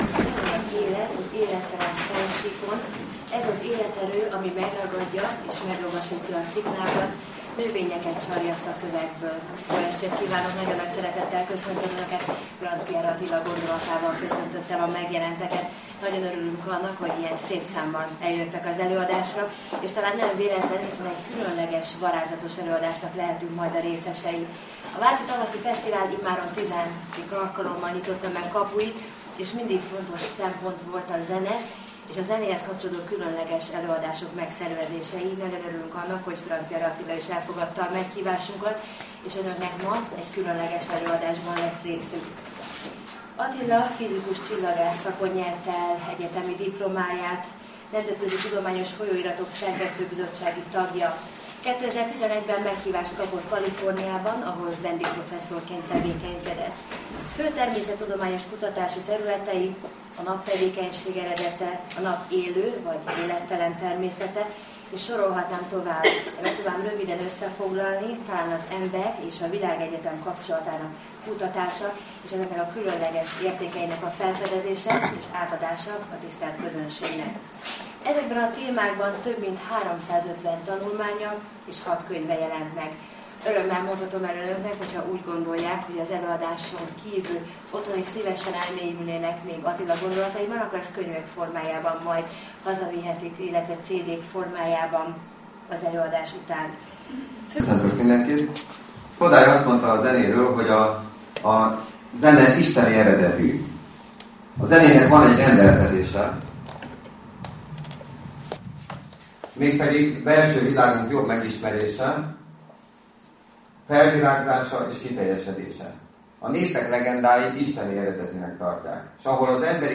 A népzene titka azonban sokkal nagyobb annál, hogy néhány mondattal világossá lehetne tenni. Ebben az előadásban (amely Vácott hangzott el, 2012. március 21-én, a Tavasz Fesztivál keretében) megvlágítom az igazi népzene titkát.